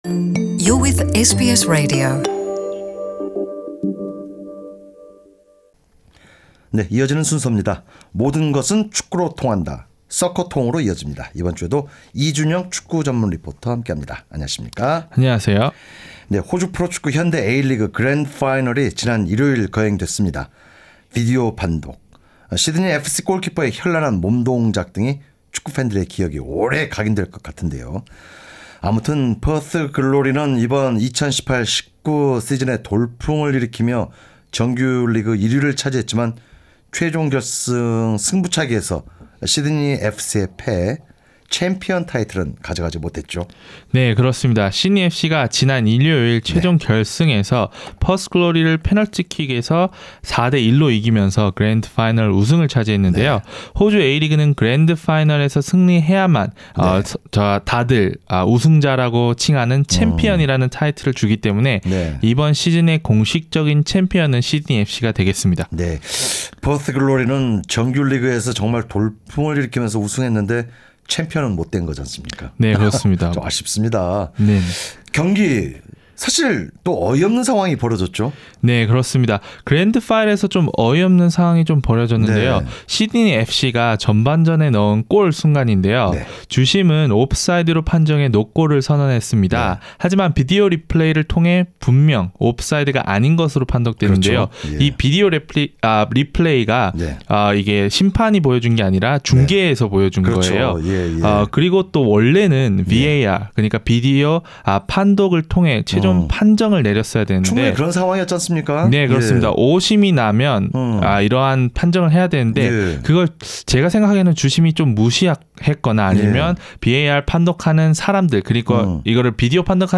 진행자: 이어지는 순섭니다.